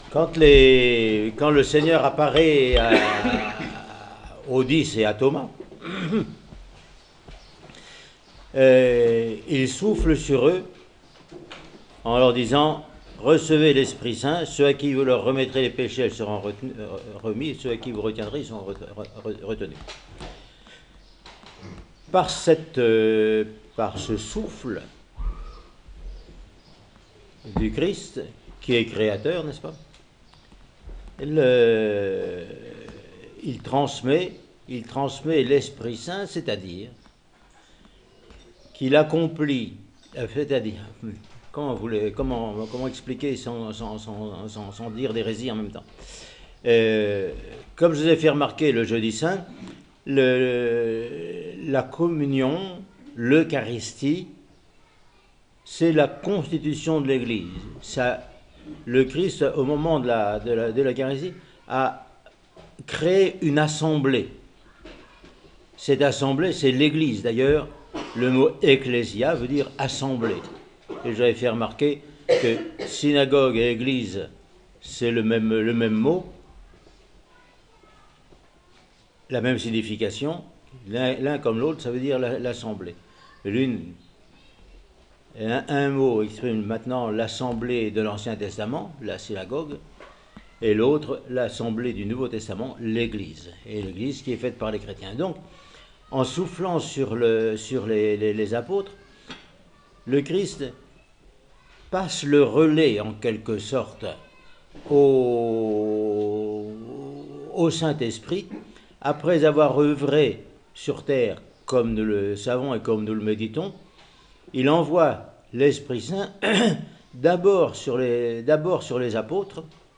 Homélie sur le dimanche de Thomas :Monastère de la Transfiguration